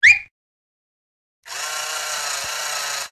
SA_fill_with_lead_miss.ogg